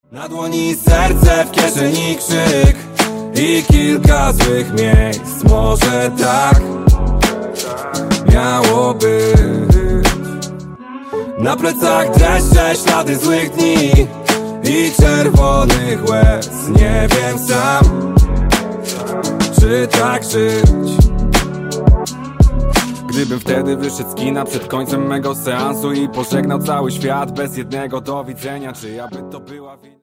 Hip-Hop/Rap